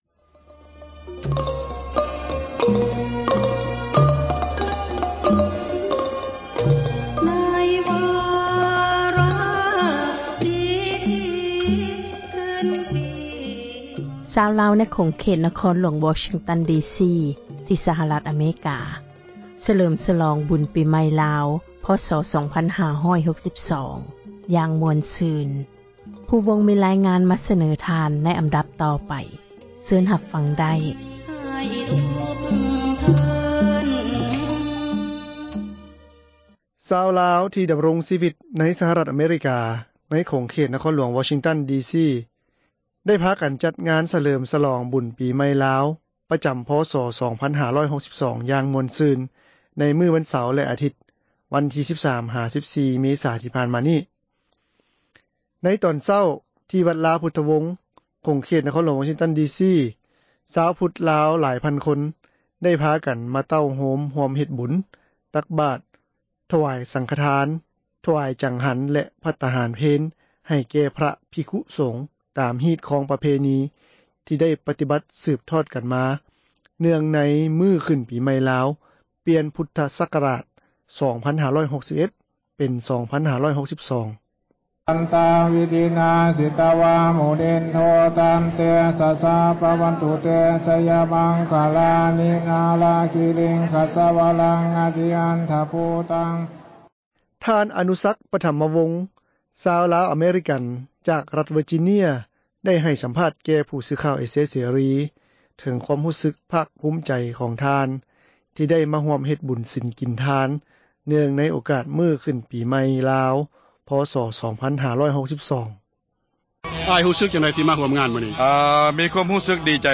“ສຽງພຣະສົງເທສນາ ໃນຣະຫວ່າງພິທີທາງສາສນາ.”
ຕົກມາຕອນບ່າຍ ກໍມີການສເລີມສລອງຢ່າງມ່ວນຊື່ນ ດ້ວຍການສະແດງສິລປດົນຕຣີ ຈາກລູກຫຼານຊຸມຊົນລາວໃນສຫະຣັຖ ເປັນຕົ້ນ ແມ່ນມີການຮ້ອງເພັງ, ການສະແດງບົດຟ້ອນອວຍພອນປີໃໝ່ ແລະການສະແດງສິລປະດົນຕຣີ ຈາກມູລນິທິ ມໍຣະດົກລາວ ເພື່ອໃຫ້ຜູ້ທີ່ ເຂົ້າມາຮ່ວມງານ ໄດ້ຮັບຊົມຮັບຟັງກັນ. “ດົນຕຣີ”.